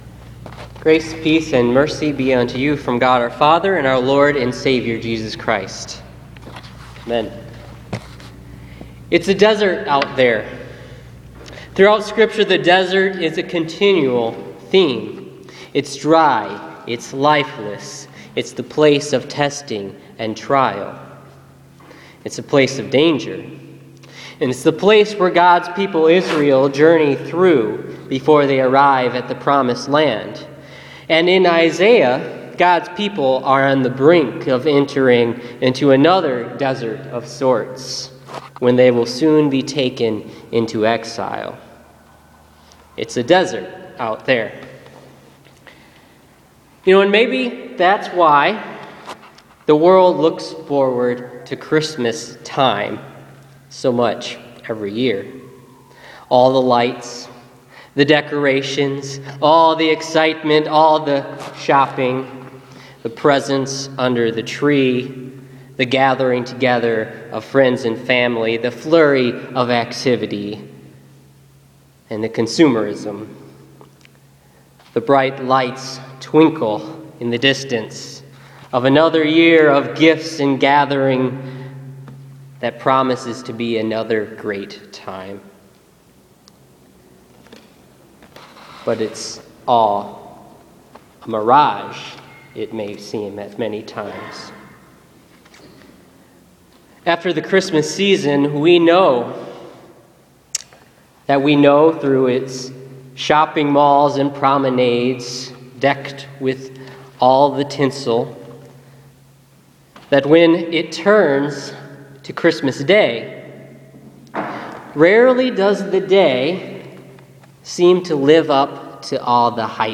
Listen to this week’s sermon on Isaiah 35:1-10 for the third week of Advent.